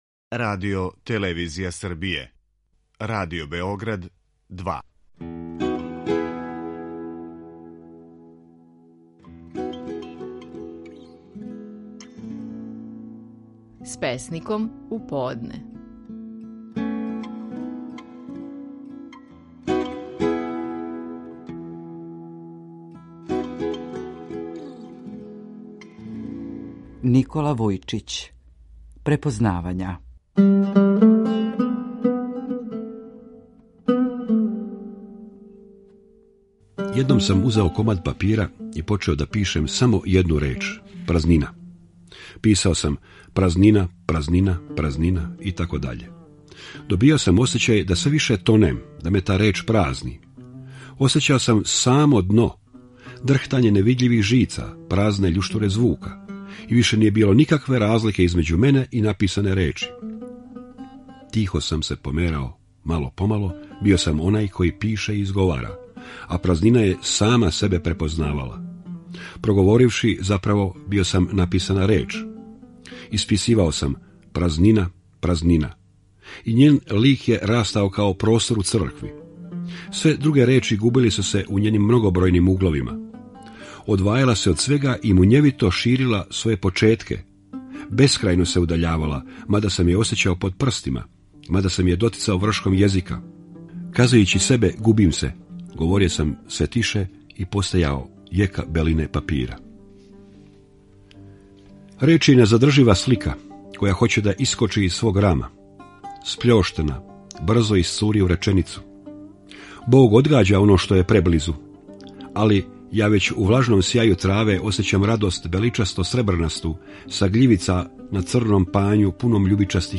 Стихови наших најпознатијих песника, у интерпретацији аутора.